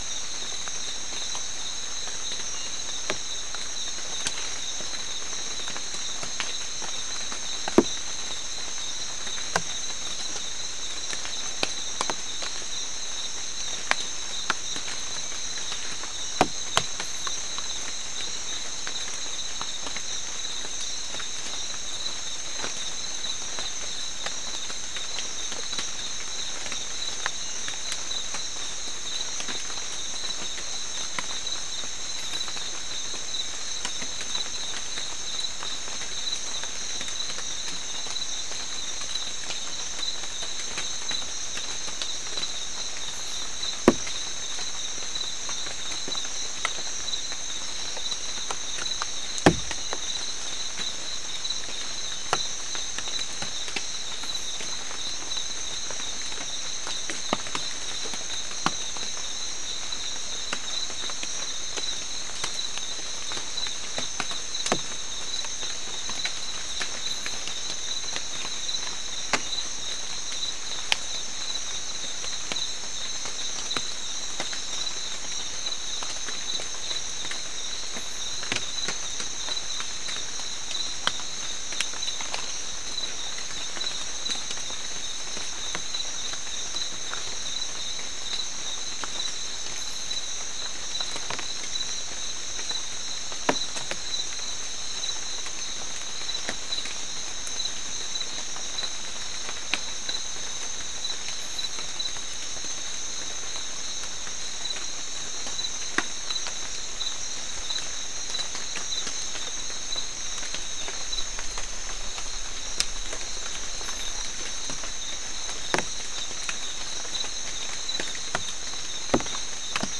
Soundscape Recording Location: South America: Guyana: Sandstone: 4
Recorder: SM3